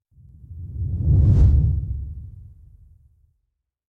Звуки катапульты
Снаряд катапульты пролетел мимо